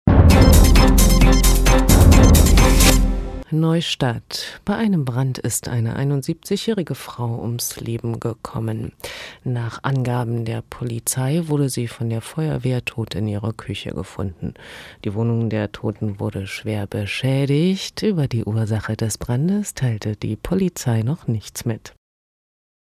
Hörbeispiel 4 „Hörerdistanz“ glocke
uninteressiert
4-Hörerdistanz.mp3